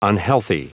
Prononciation du mot unhealthy en anglais (fichier audio)
Prononciation du mot : unhealthy